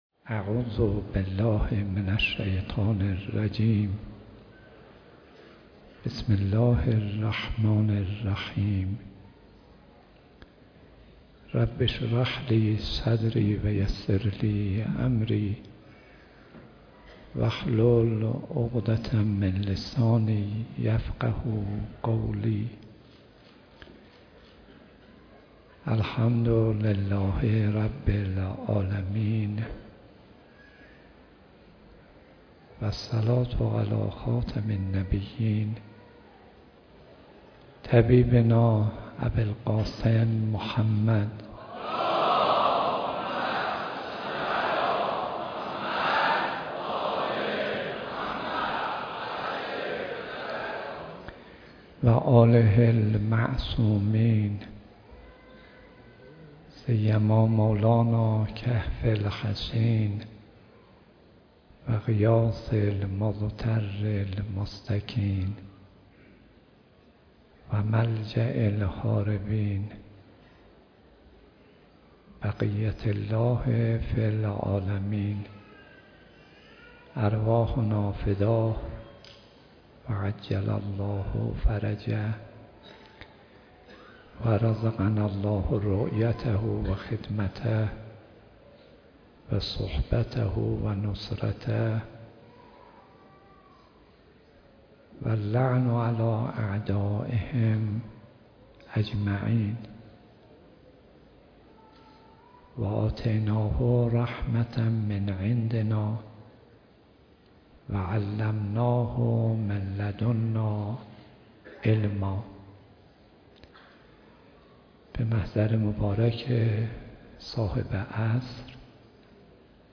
مراسم ترحیم عالم ربانی و معلم اخلاق آیت الله خوشوقت برگزار شد
سخنرانی حجت الاسلام جناب آقای صدیقی